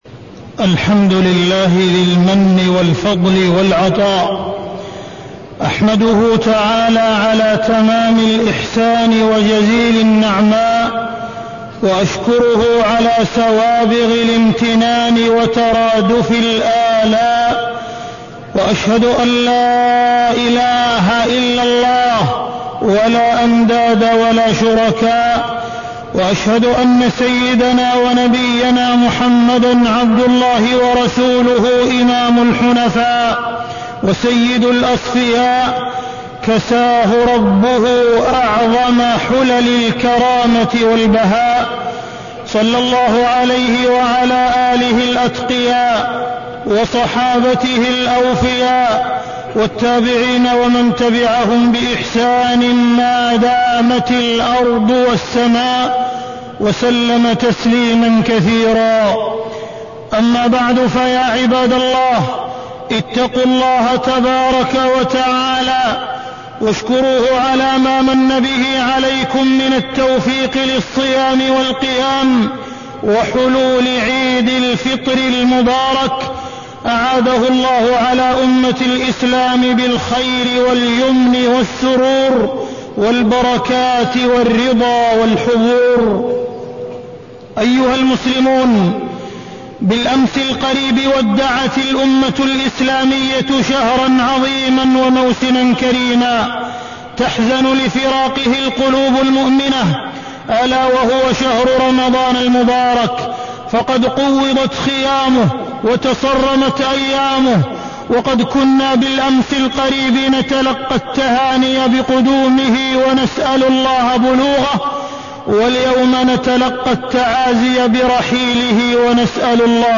تاريخ النشر ٢ شوال ١٤٢٣ هـ المكان: المسجد الحرام الشيخ: معالي الشيخ أ.د. عبدالرحمن بن عبدالعزيز السديس معالي الشيخ أ.د. عبدالرحمن بن عبدالعزيز السديس فراق رمضان وعيد الفطر